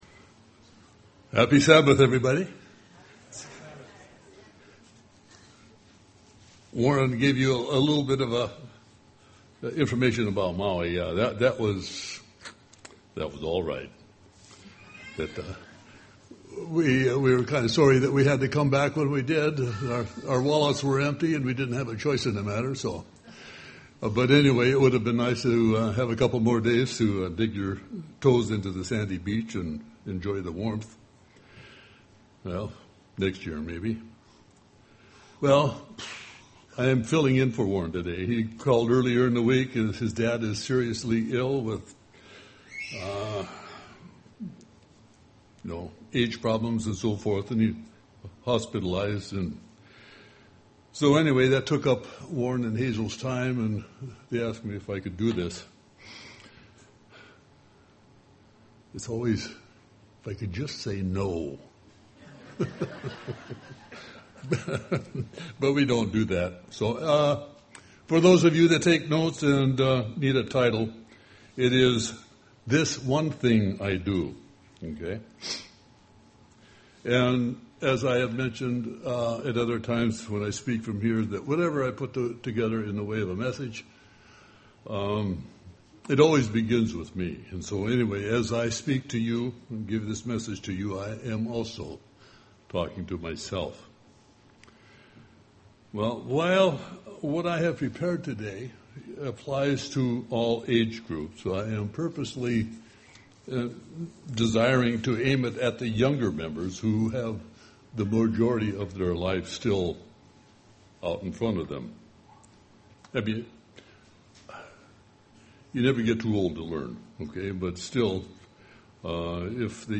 Given in Seattle, WA
Print Involving God in making choices UCG Sermon